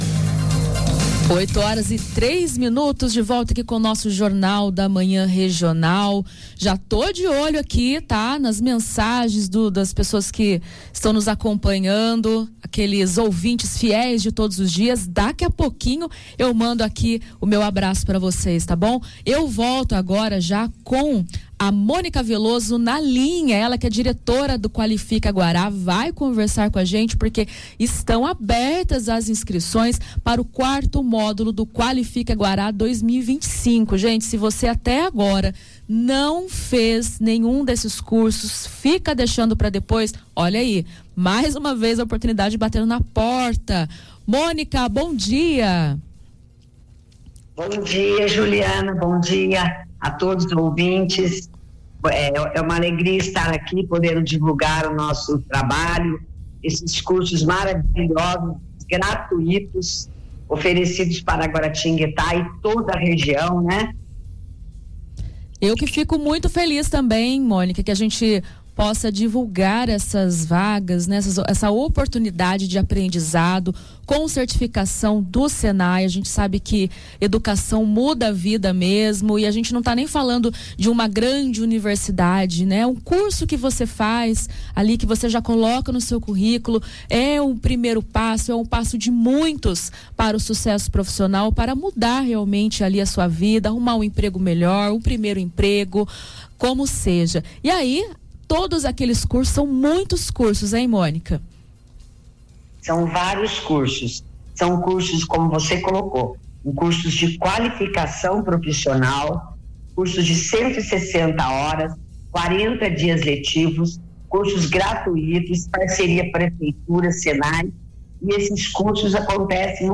Ao vivo no Jornal da Manhã Regional